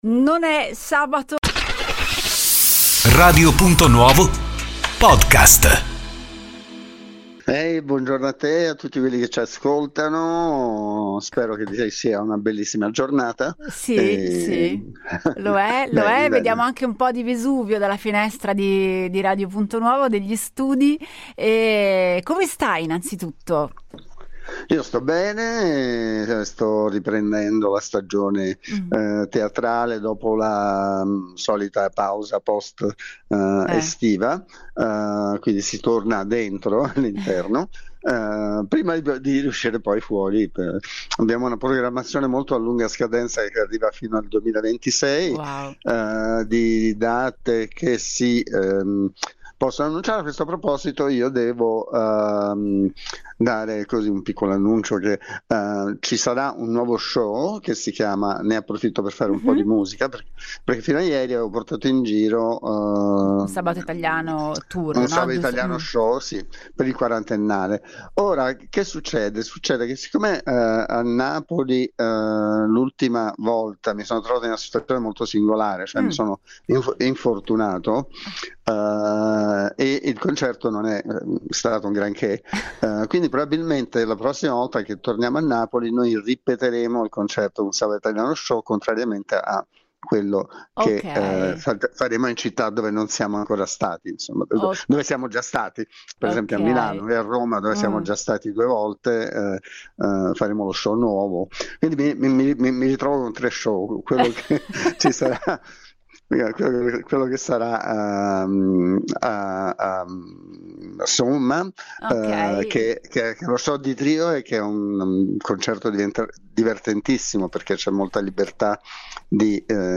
Sergio Caputo, il maestro della musica italiana, è stato ospite di Radio Punto Nuovo per una chiacchierata a tutto tondo sulla sua carriera, i suoi progetti futuri e il suo rapporto con Napoli.